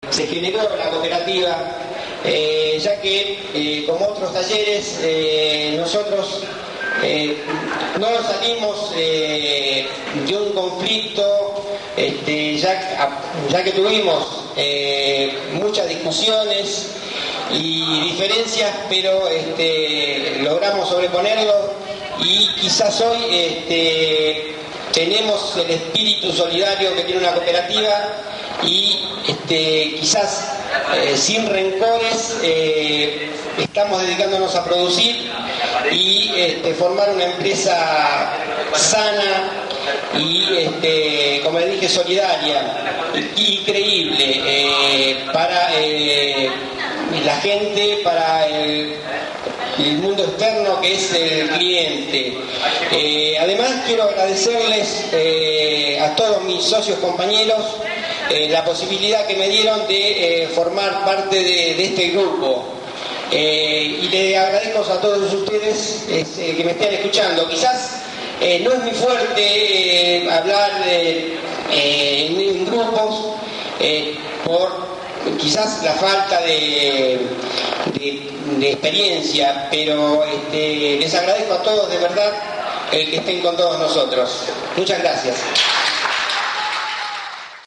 En un edificio construido a mediados del Siglo XX se realizo ese día un acto con la presencia de todos los trabajadores y trabajadoras de la cooperativa, de otras empresas recuperadas, la Red Gráfica Cooperativa, delegados otras empresas gráficas y la comisión directiva del sindicato Federación Gráfica bonaerense.